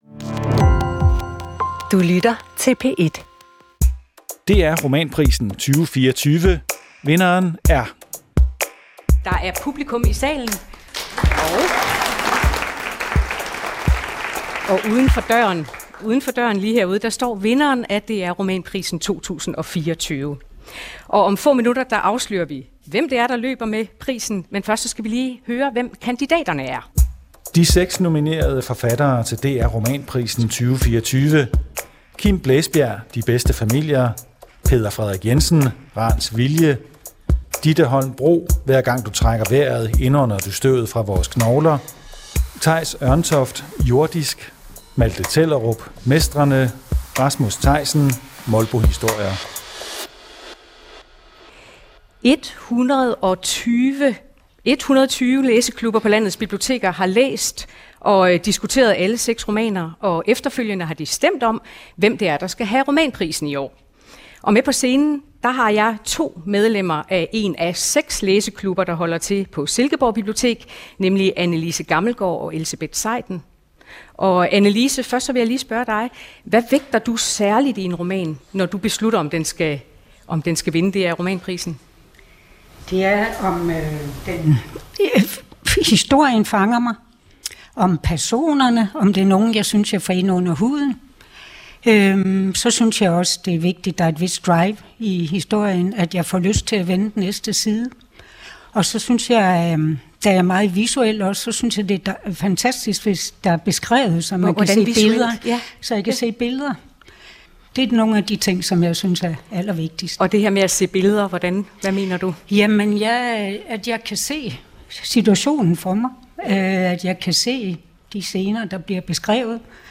Vi afslører vinderen af DR Romanprisen 2024 og fejrer forfatteren med gæster på scenen og publikum i salen.